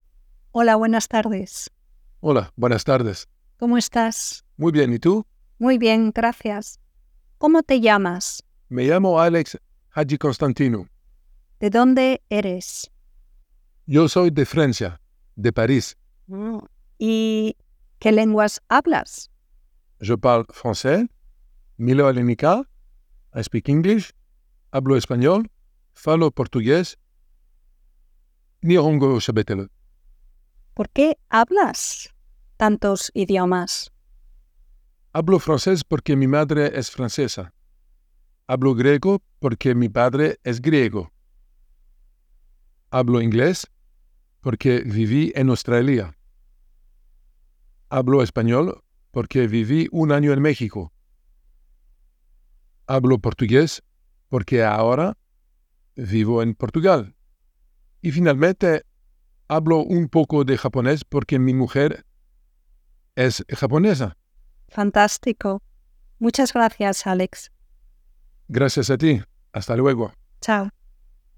Audio exercise